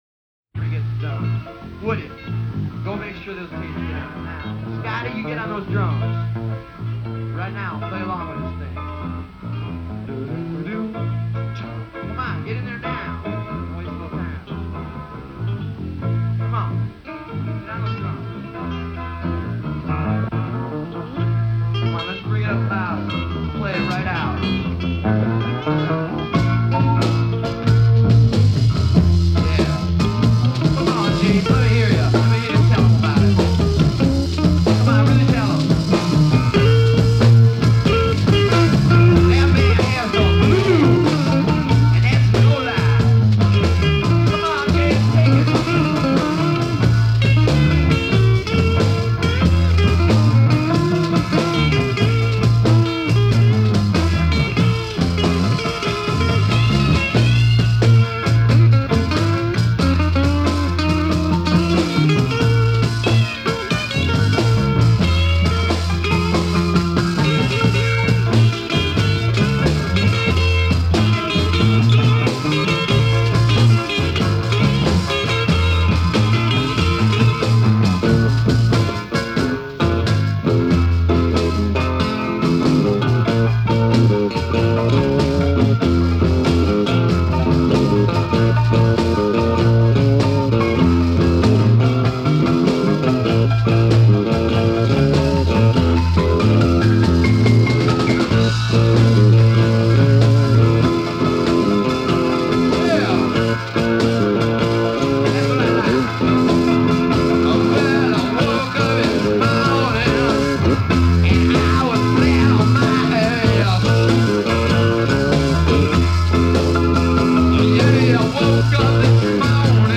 Genre : Rock
New York & Detroit Reherarsals, 1973